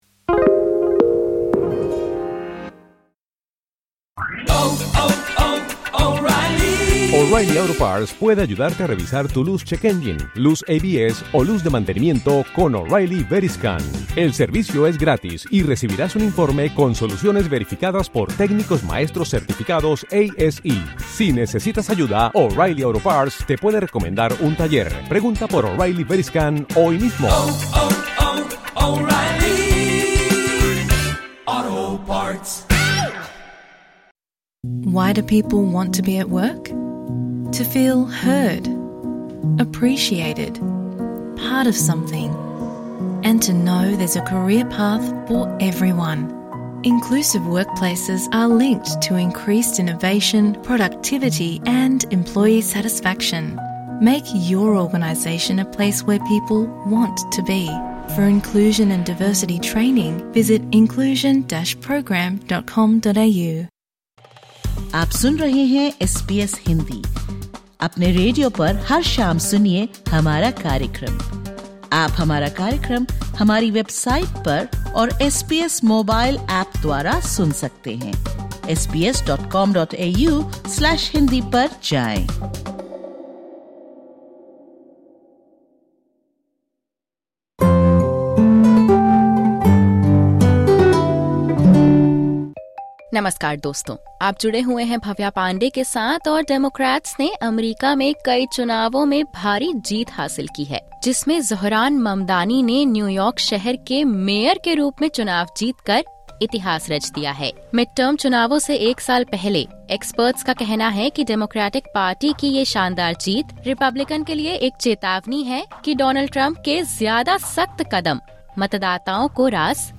Zohran Mamdani has been elected mayor of New York City, becoming the city’s first Muslim, South Asian, and Democratic socialist leader, and its youngest in more than a century. In this SBS Hindi podcast, we hear from Indian Australian community leaders on how they view Mamdani’s rise and its global significance.